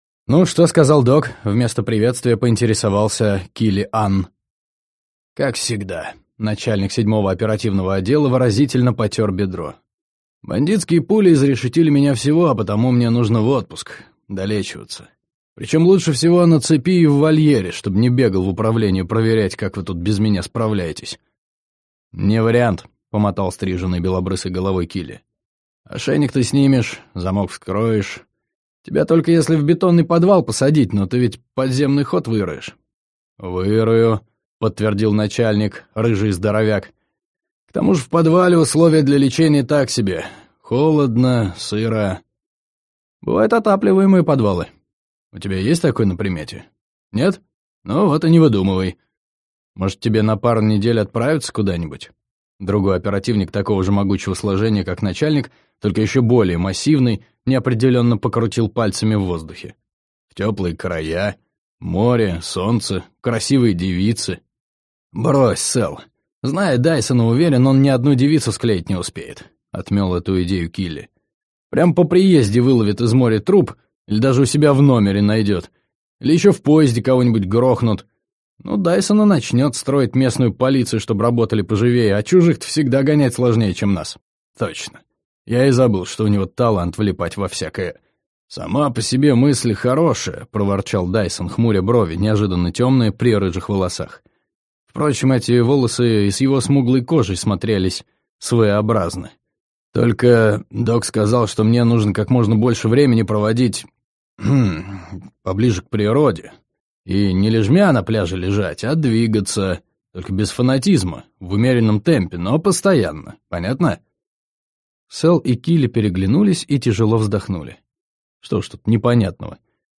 Аудиокнига Пес и его девушка | Библиотека аудиокниг